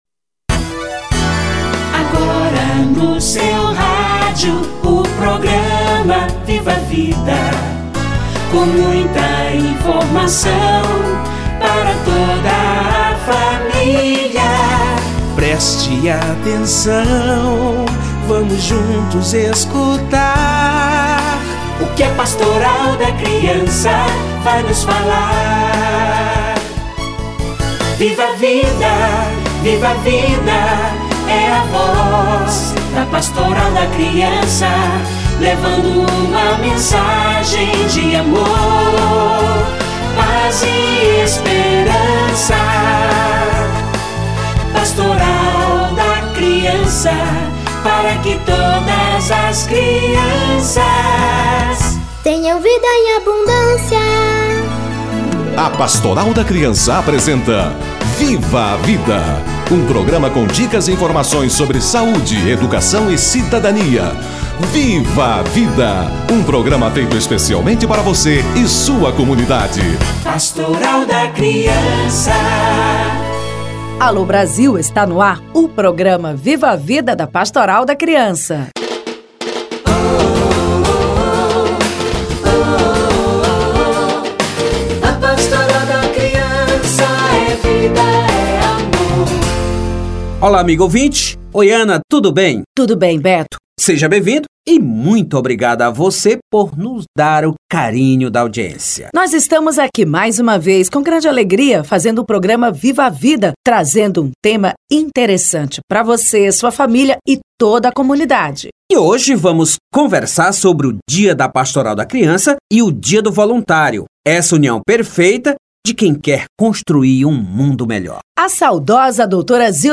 Ano da Paz / Dia da Pastoral da Criança - Entrevista